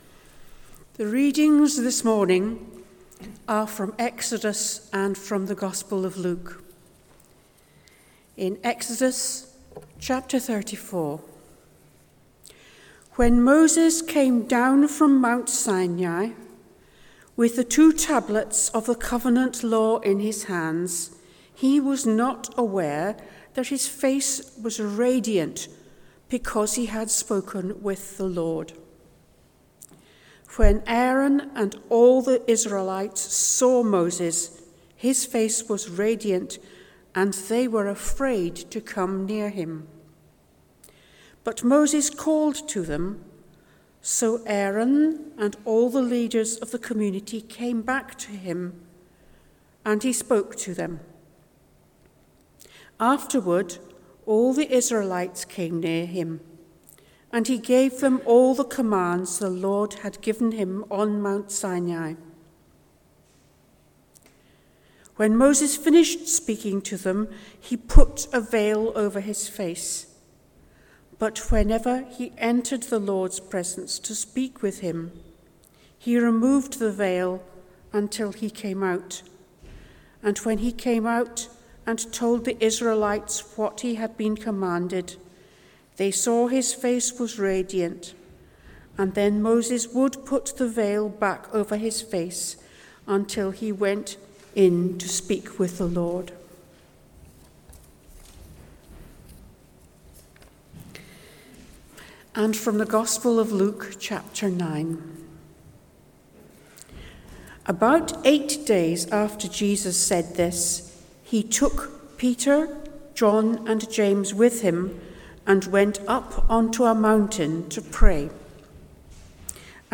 Passage: Exodus 34:29-35 & Luke 9:28-50 Service Type: Sunday Morning